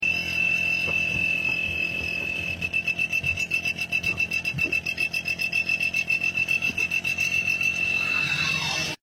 125 sound black silencer👑👑👑 sound effects free download